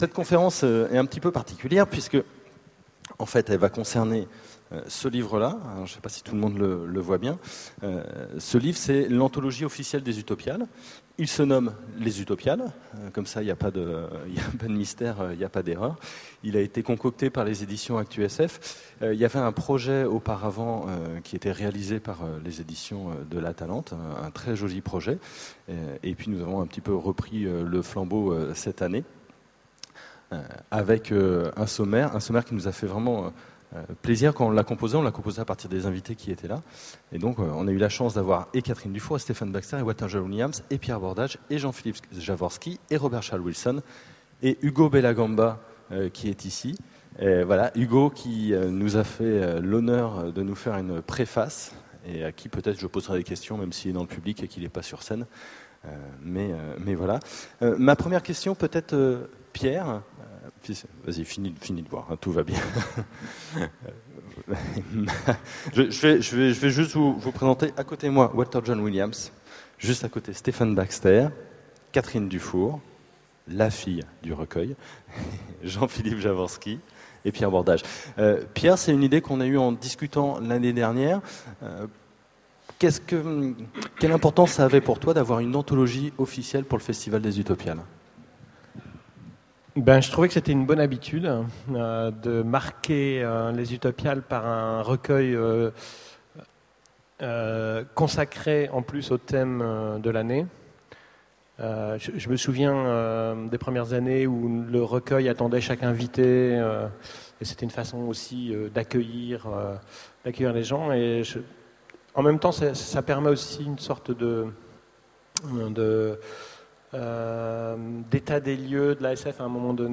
Voici l'enregistrement de la conférence sur l'anthologie du festival aux Utopiales 2009.